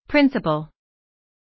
しかも、発音も全く一緒。